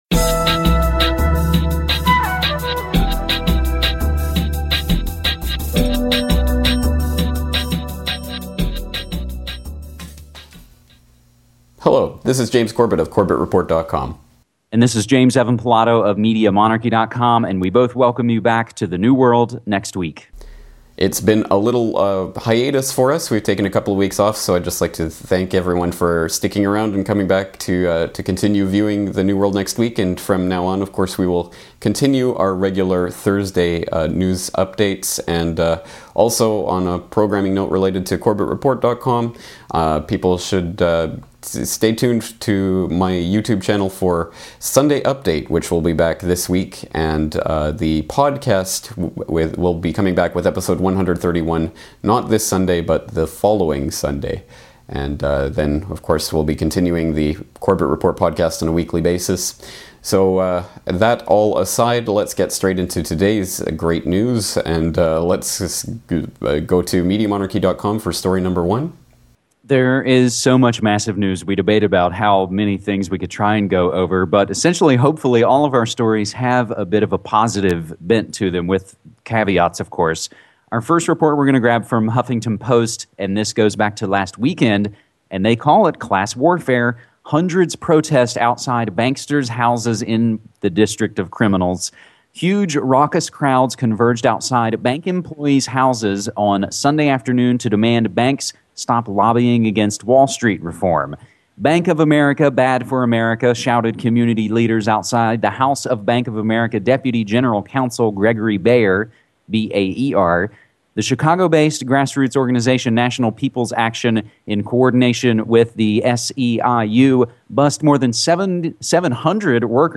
Interview 170